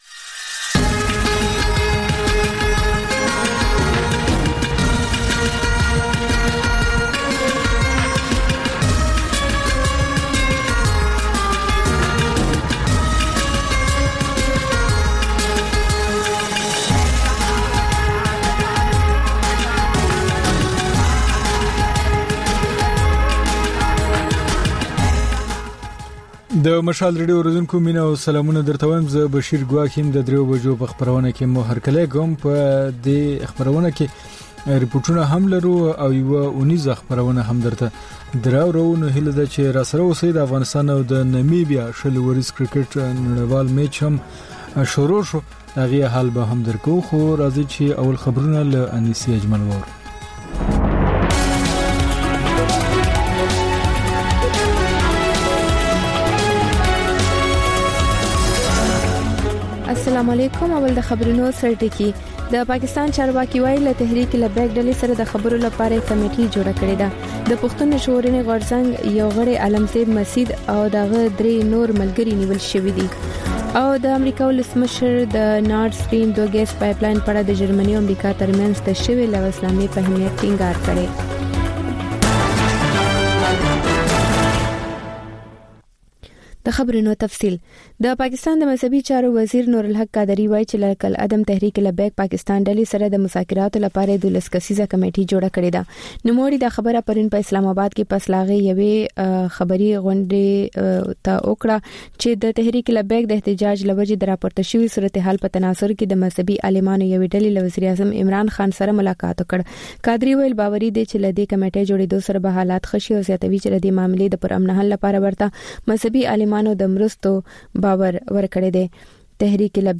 د مشال راډیو درېیمه یو ساعته ماسپښینۍ خپرونه. تر خبرونو وروسته، رپورټونه او شننې خپرېږي. ورسره اوونیزه خپرونه/خپرونې هم خپرېږي.